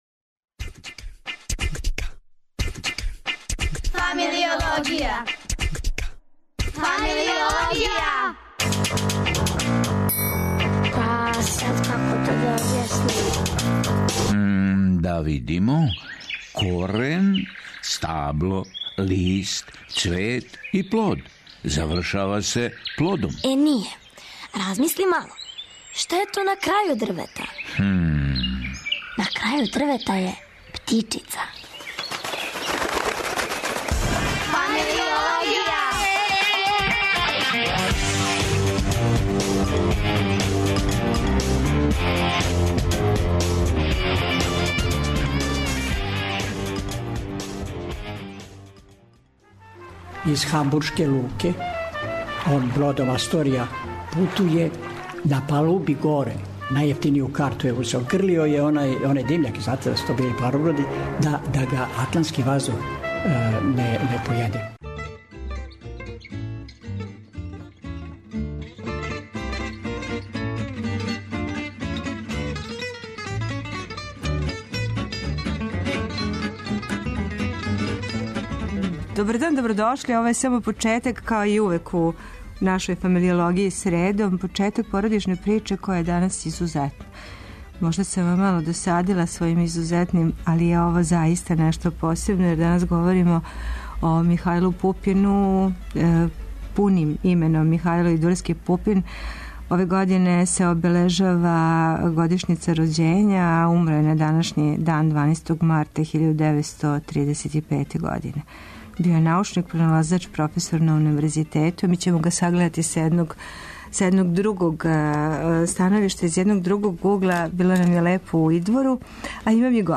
Ишли смо у Идвор...